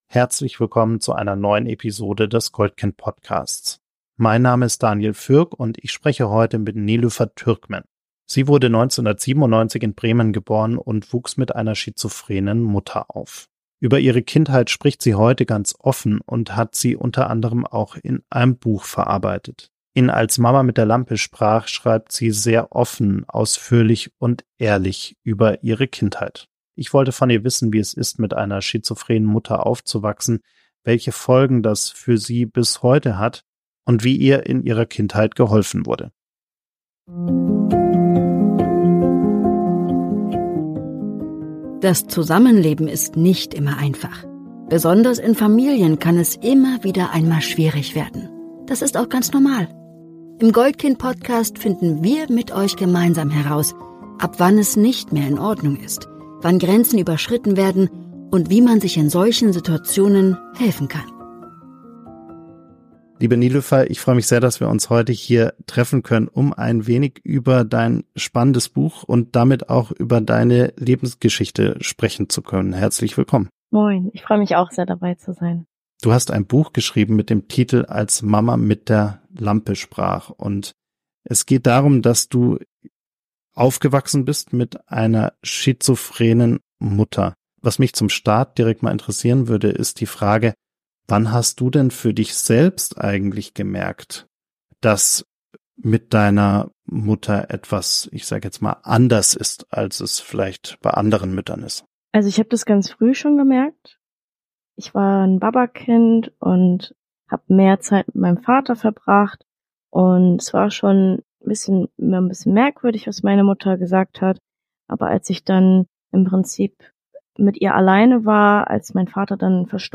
Ein Gespräch über Parentifizierung, Resilienz, gesellschaftliches Schweigen – und über die Kraft des offenen Umgangs mit psychischer Erkrankung.